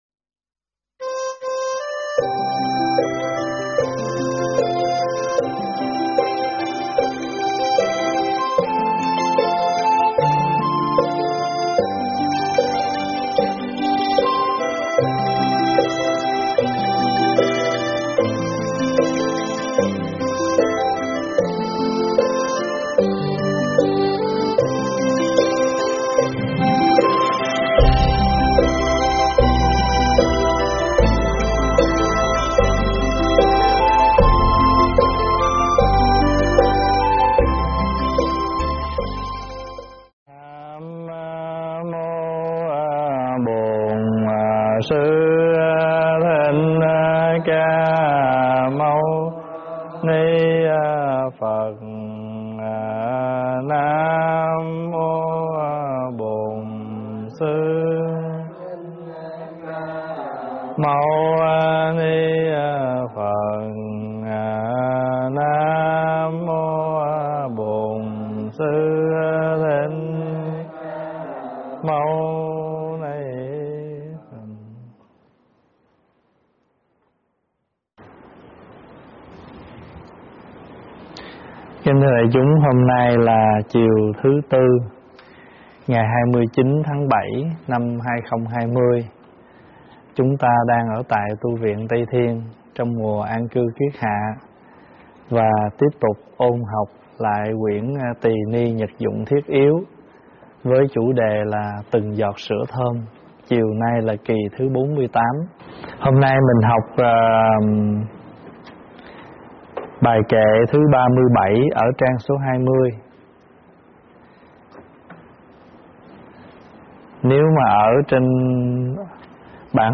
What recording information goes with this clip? giảng tại Tv Tây Thiên